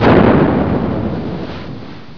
explosao.wav